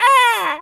crow_raven_squawk_03.wav